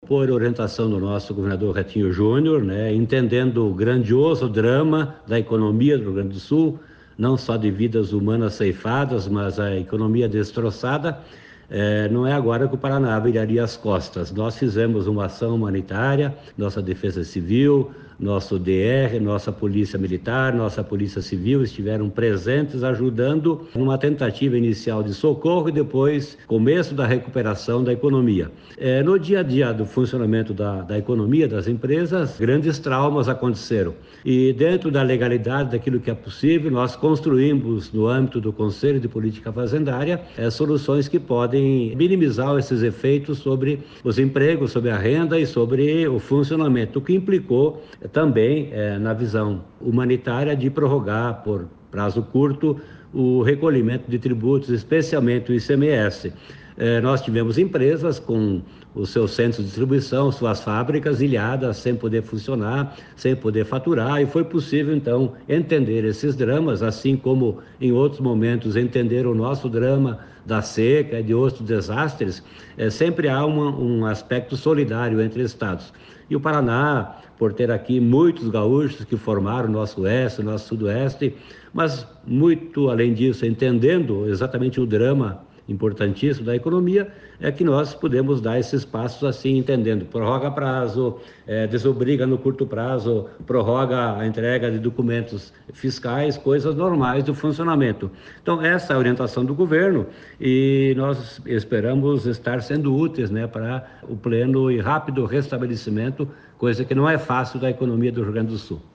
Sonora do secretário da Fazenda, Norberto Ortigara, sobre as medidas tributárias adotadas em apoio ao Rio Grande do Sul